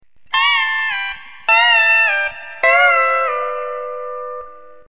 Honky Tonk #3. Key of G. Learning to use pedals "B" and "C" in combination. Slur
up to the 16th fret from the 15th fret to begin each lick.  The sloppier the